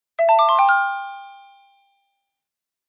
Emergency_Alert01-1-C.ogg